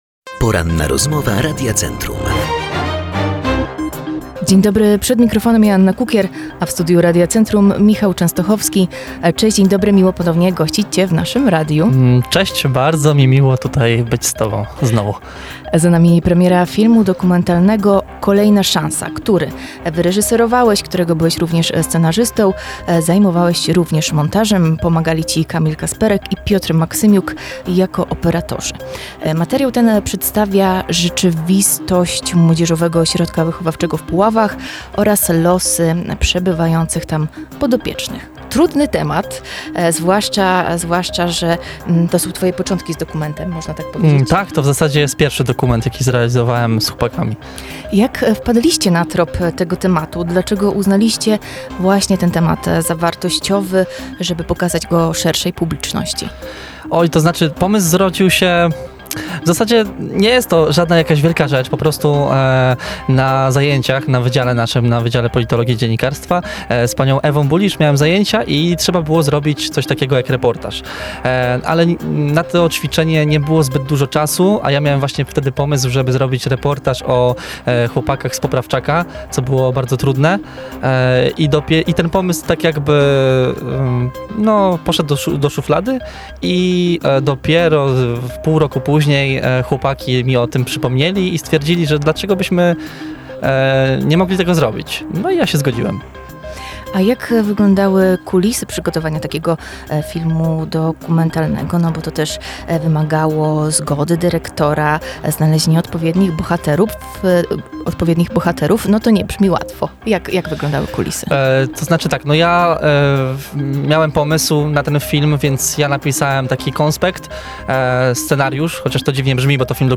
Za nami Poranna Rozmowa Radia Centrum.